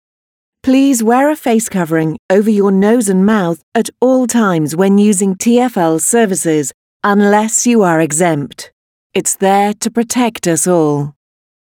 Bus-Infotainment--IBus-/assets/audio/manual_announcements/facecovering.mp3 at e5a8d78bf128a8edcf4c44b64cb6b2afcdac202e